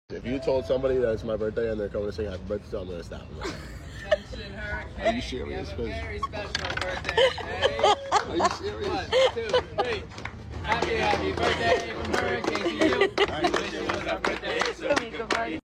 Not him clapping 💀 sound effects free download
You Just Search Sound Effects And Download. funny sound effects on tiktok Download Sound Effect Home